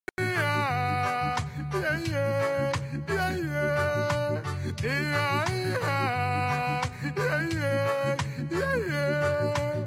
ia ia aaa ye yee Meme Sound Effect
This sound is perfect for adding humor, surprise, or dramatic timing to your content.